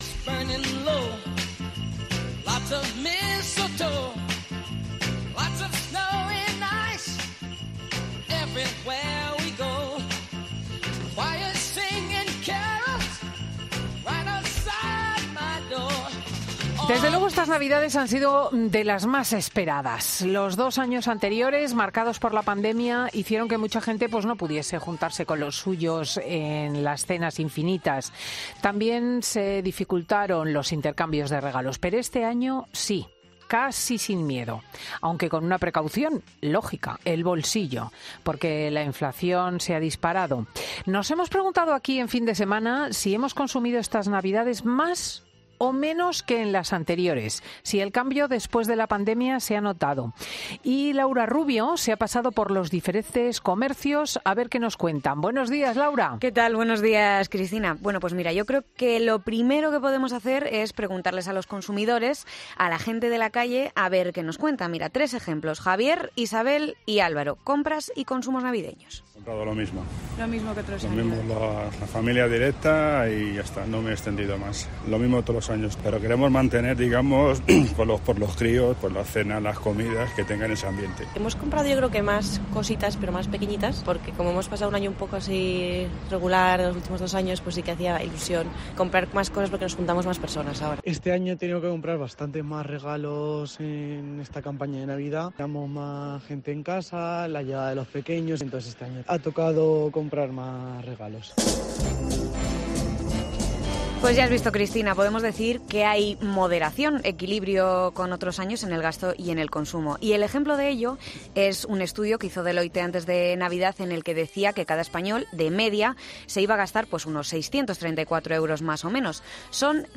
"Lo mismo que otros años. He regalado a la familia directa. Queremos mantener la costumbre por los crios, las comidas, la familia...", dice un hombre.